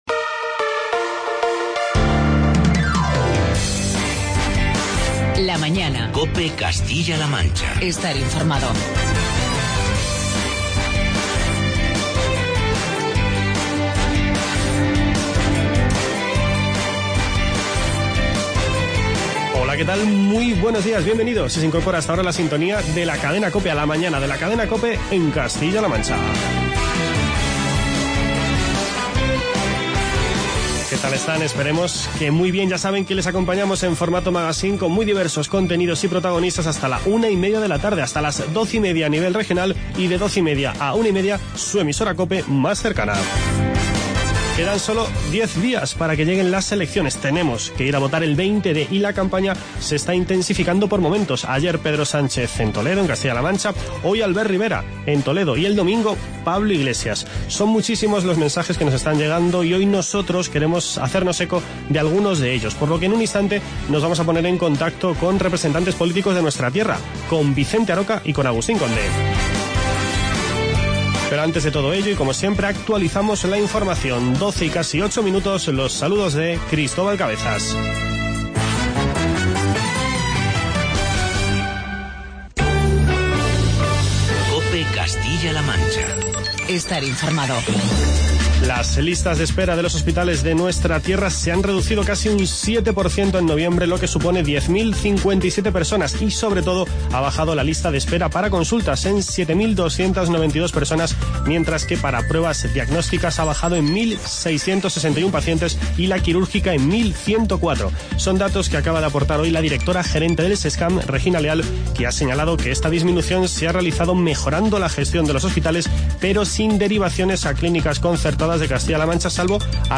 Nos metemos de lleno en la campaña electoral con las entrevistas a Vicente Aroca y Agustín Conde.